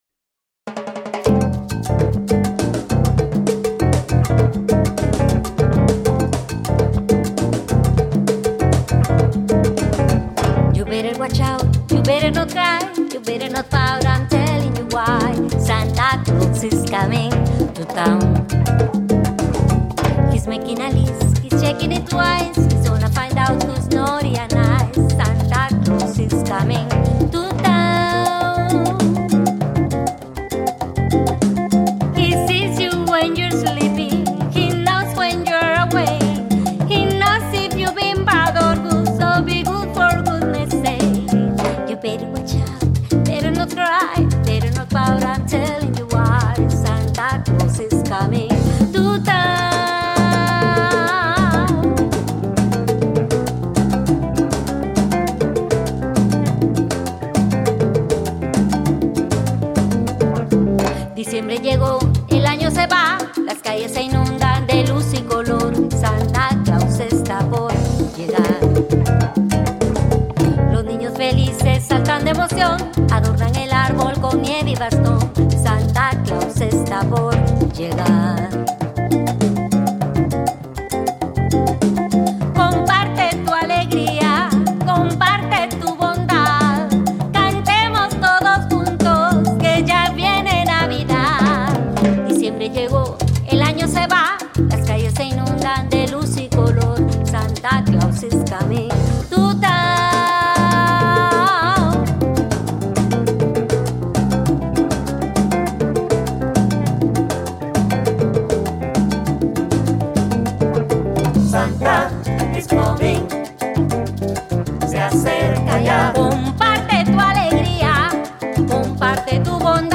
è stato registrato da musicisti del panorama salsero.